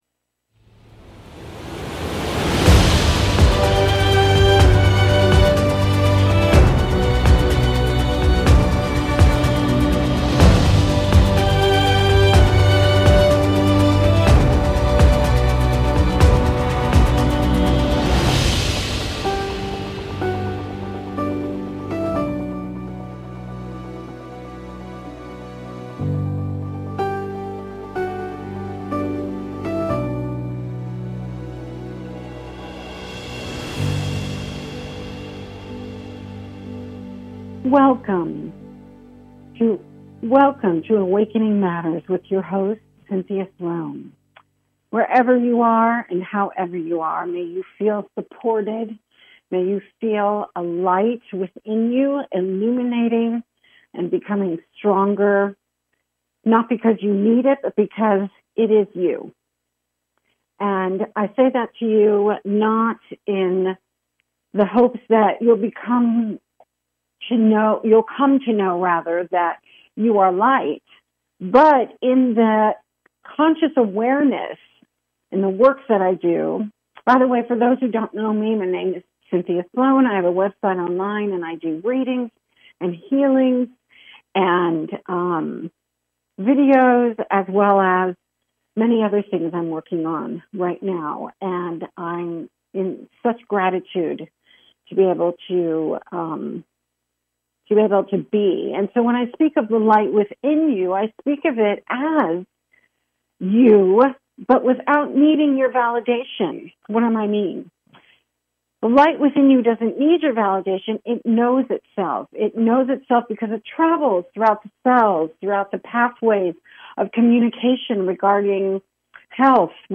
Talk Show Episode
A spiritual dialogue that invites divine wisdom, joy and laughter.